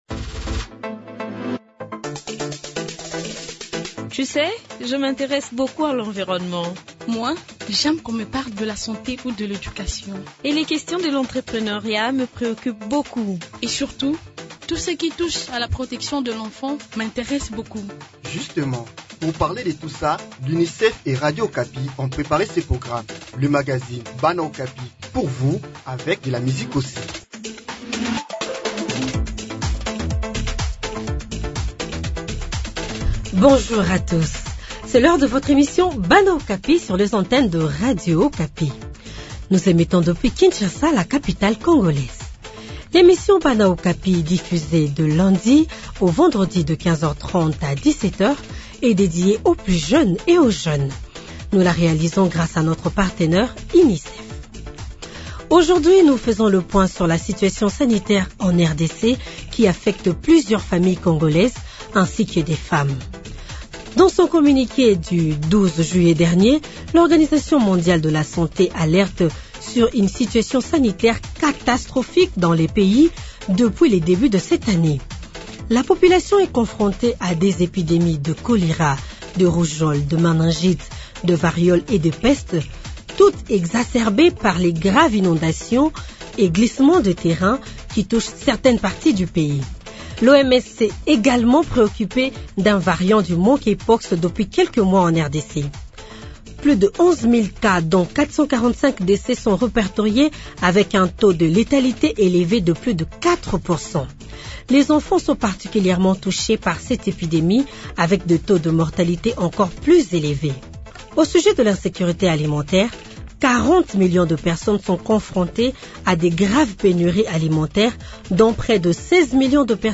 Pour faire le point de toute cette situation, nous serons dans les provinces du Nord, Sud-Kivu, du Maniema, de l’Ituri ainsi que dans le Haut-Katanga, avec nos différents reporters et nos invités.